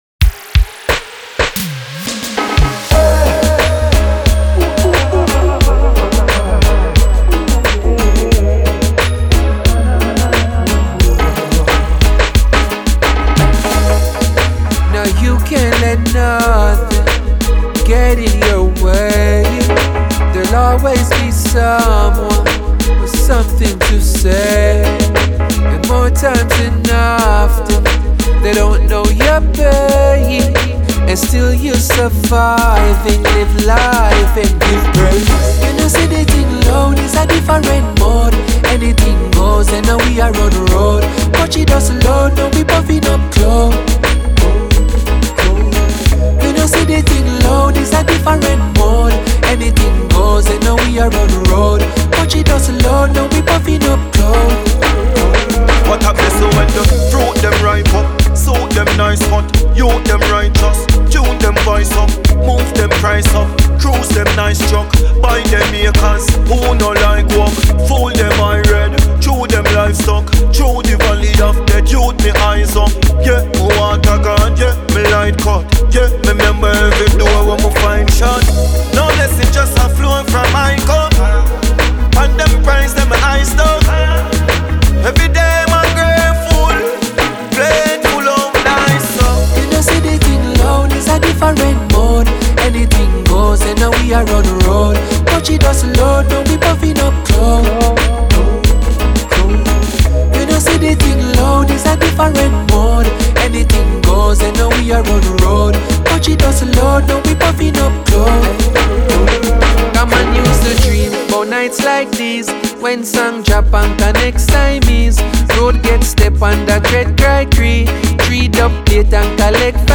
DancehallMusic